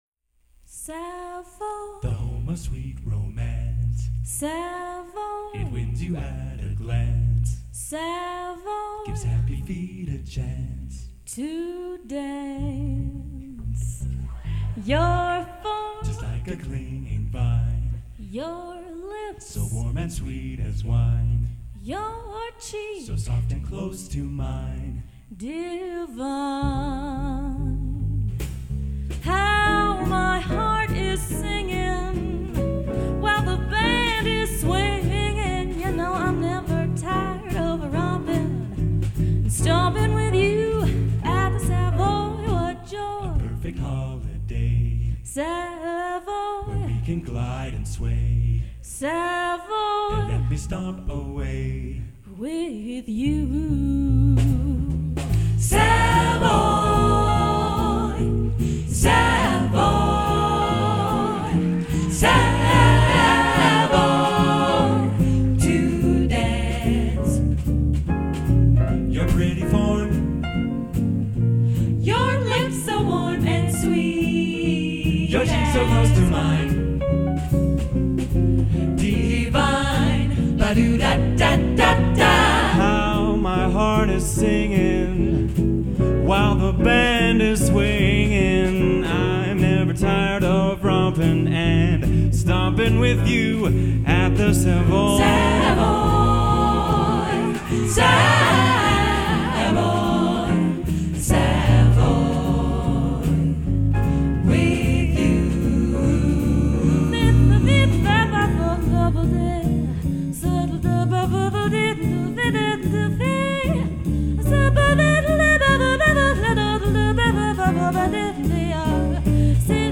Live, Banff, 2004